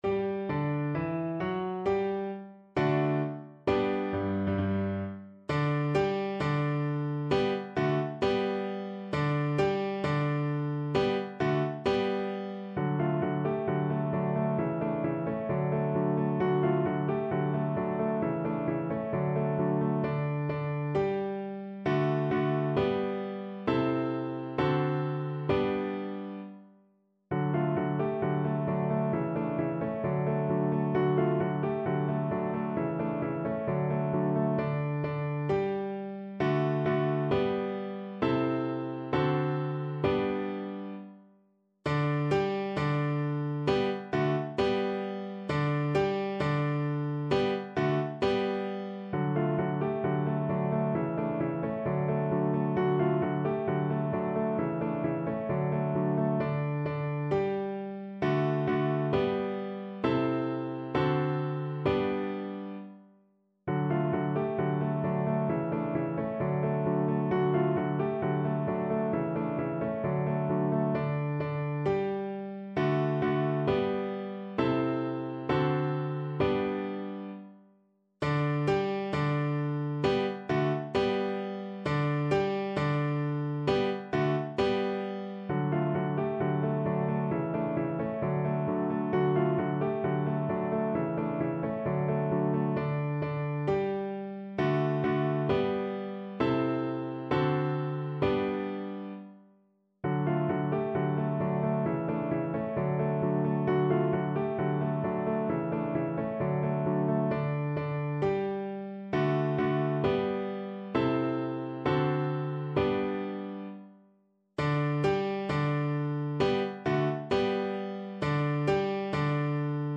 kolęda: Tryumfy króla niebieskiego (na flet i fortepian)
Symulacja akompaniamentu